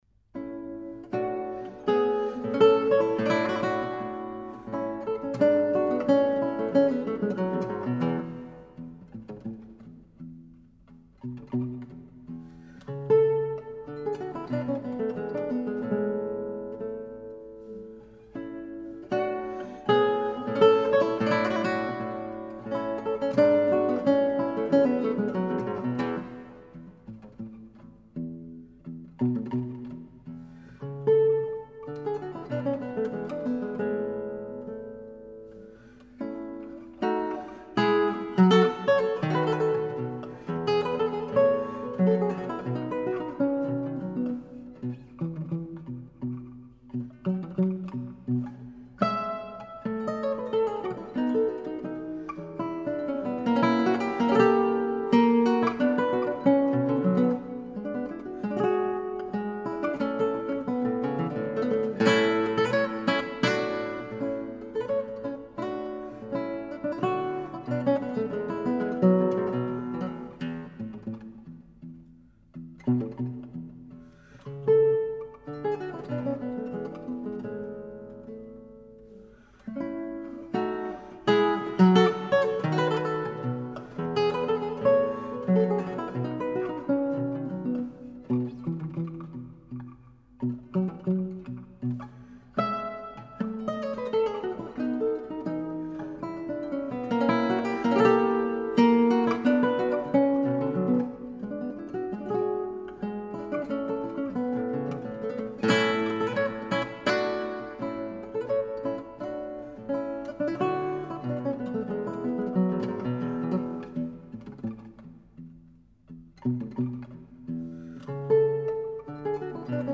Alexandre Lagoya, Guitar